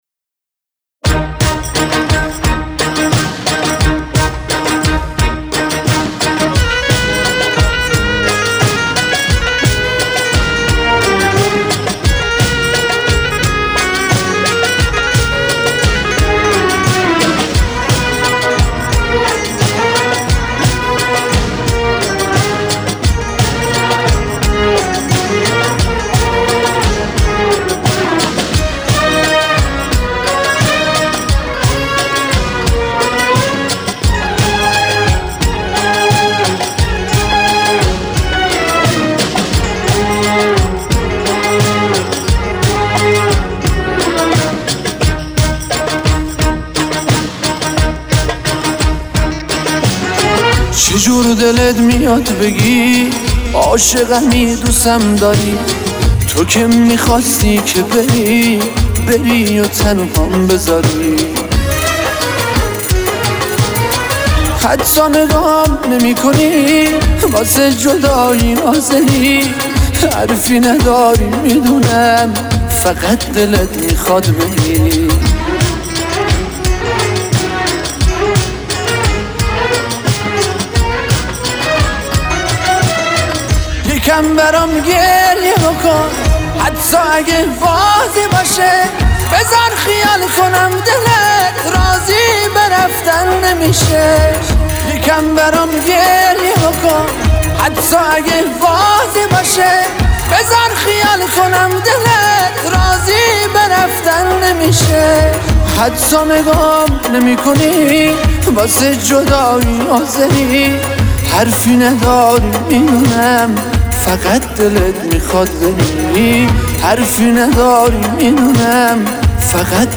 یک آهنگ کردی زیبا